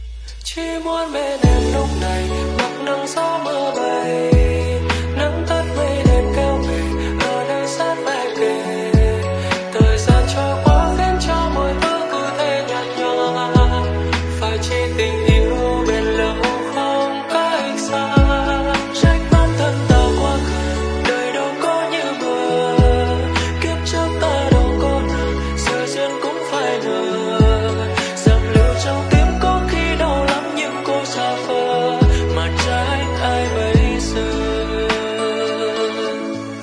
(Lofi)